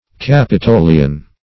Capitolian \Cap`i*to"li*an\, Capitoline \Cap"i*to*line\, a. [L.